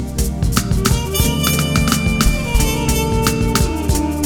Trumpet Mess-G.wav